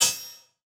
Buttonv2.wav